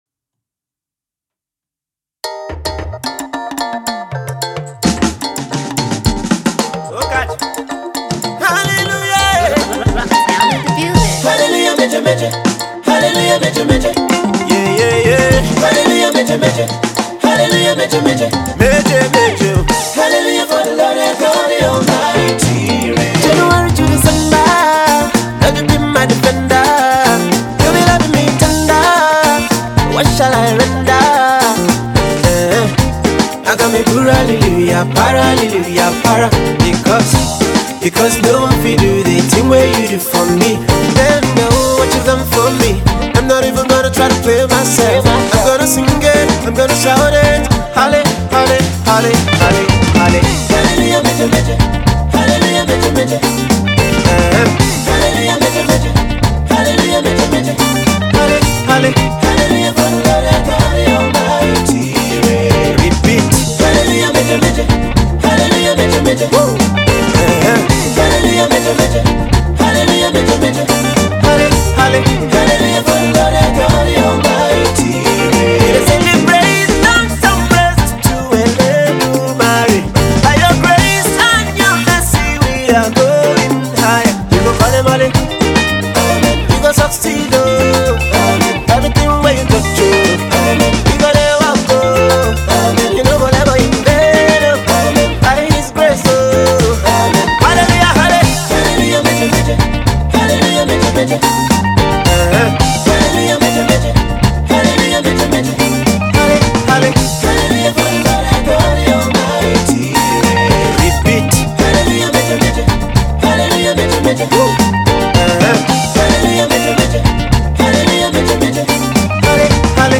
In the spirit of the holiday season, here is a new jam to